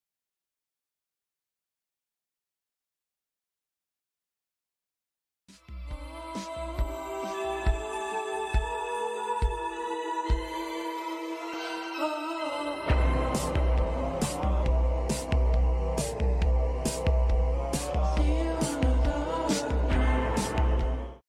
im sorry for bad quality idk what happened